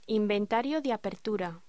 Locución: Inventario de apertura
voz